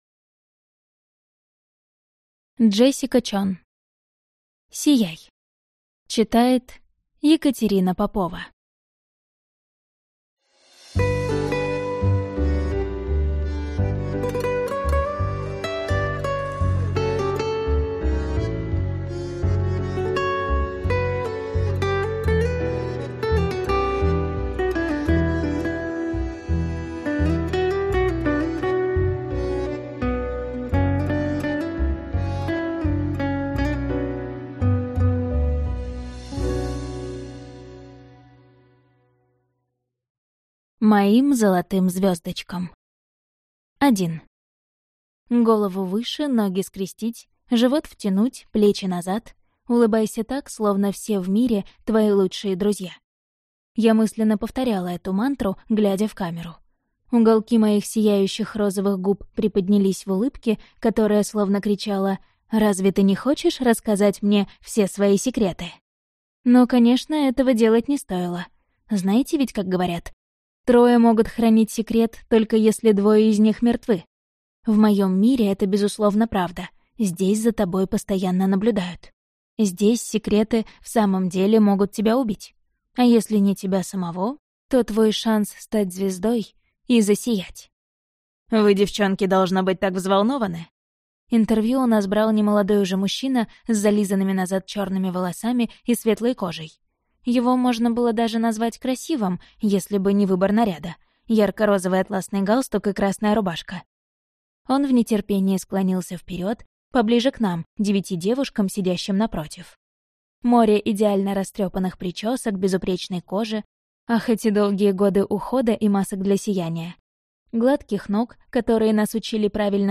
Аудиокнига Сияй | Библиотека аудиокниг